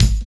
43 KICK.wav